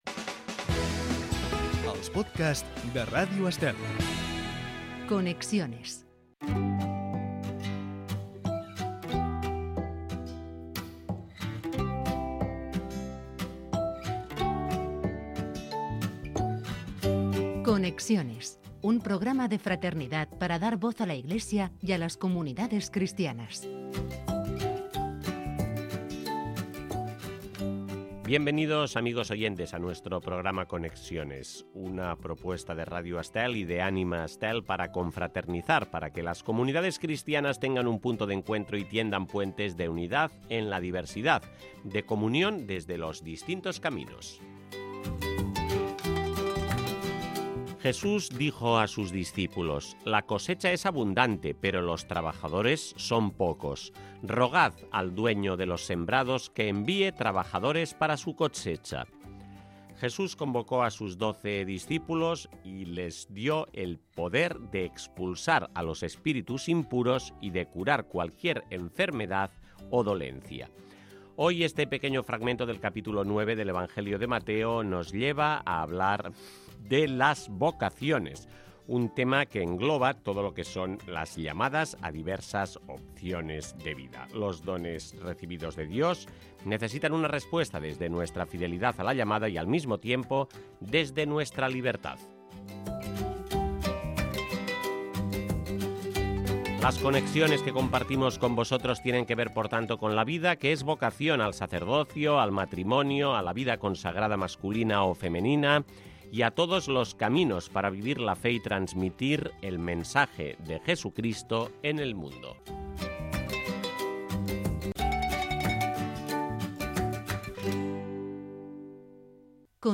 Podcast de contenido religioso y social con un reportaje sobre comunidades cristianas o vínculos entre distintas realidades y una entrevista en profundidad.